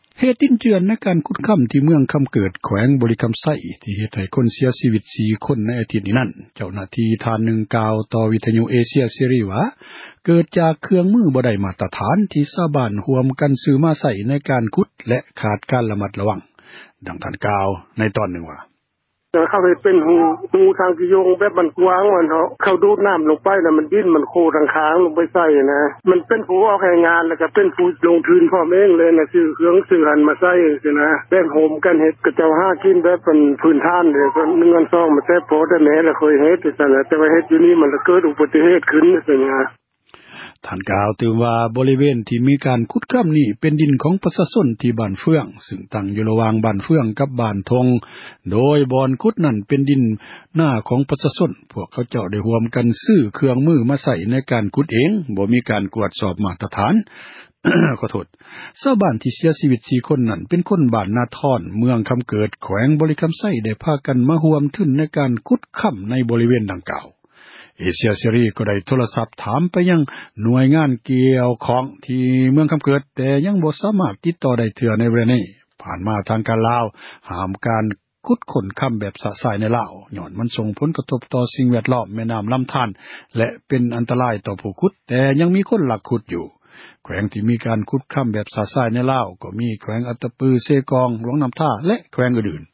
ສາເຫດດິນເຈື່ອນ ໃນການຂຸດຄຳ ທີ່ ເມືອງຄຳເກີດ ແຂວງ ບໍຣິຄຳໄຊ ທີ່ເຮັດໃຫ້ ເສັຽຊີວິດ 4 ຄົນ ໃນອາທິດນີ້ ນັ້ນ, ເຈົ້າຫນ້າທີ່ ທ່ານນຶ່ງ ກ່າວຕໍ່ ວິທຍຸ ເອເຊັຽເສຣີ ວ່າຍ້ອນເຄື່ອງມື ບໍ່ໄດ້ ມາຕຖານ ທີ່ຊາວບ້ານ ຮ່ວມກັນ ຊື້ມາໃຊ້ ໃນການຂຸດ ແລະຂາດຄວາມ ຣະມັດຣະວັງ. ດັ່ງທ່ານກ່າວ ໃນຕອນນຶ່ງວ່າ: